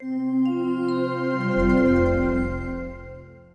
Nothing per default, but when installing all audio files in setup and load the default sound scheme you hear the logoff sound.
win98logoff.mp3